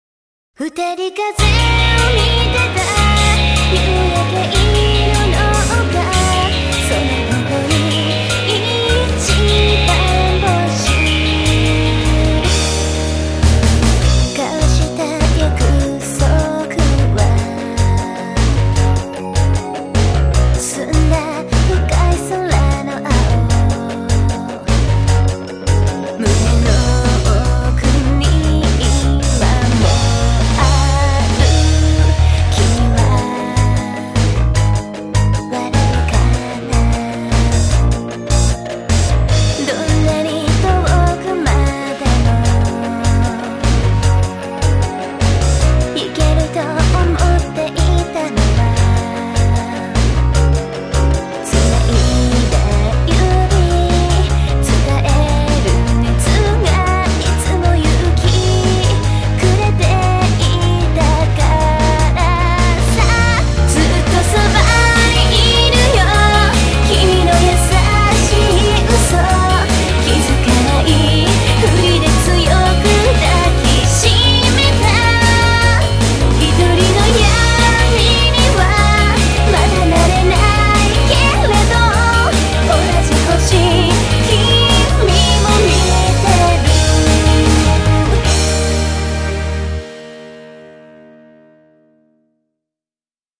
主題歌